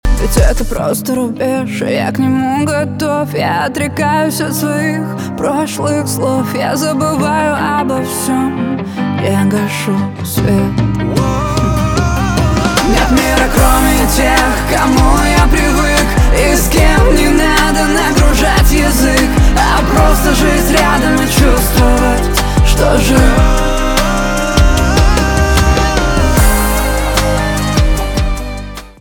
поп , русские
нарастающие , битовые , гитара , классные , чувственные